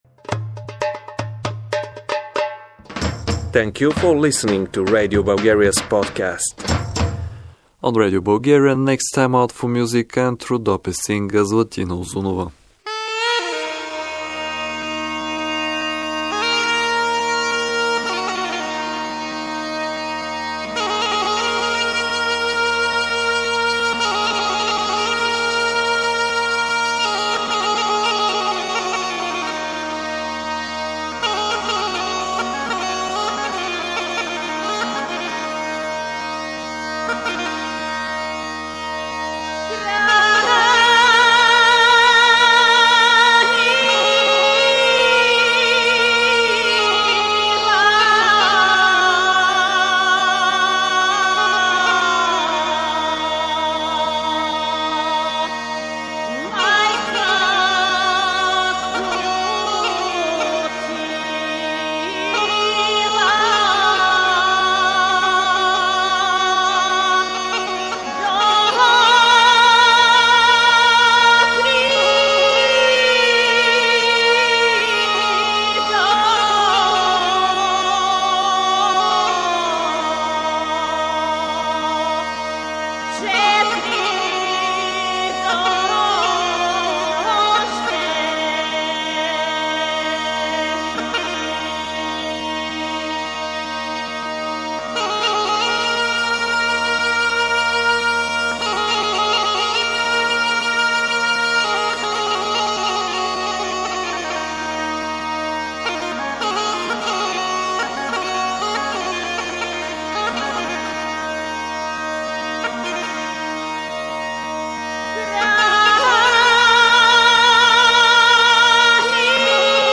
Rhodope songs